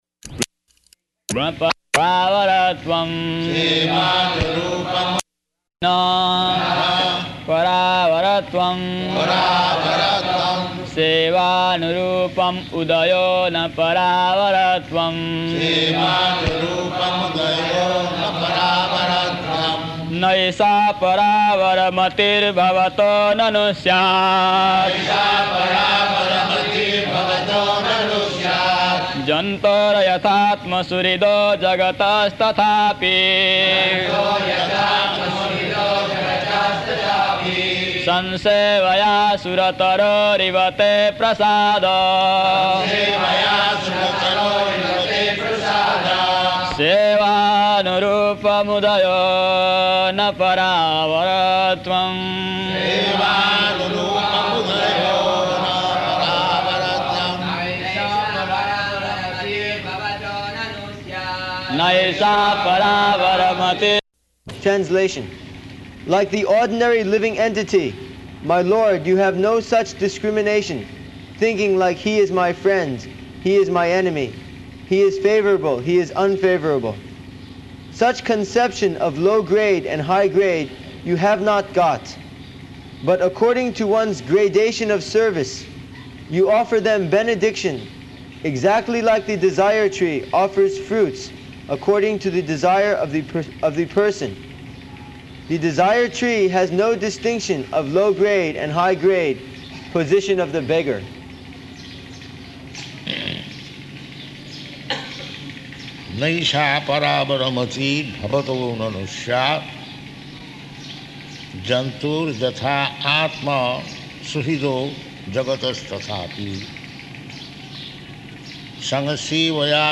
Location: Māyāpur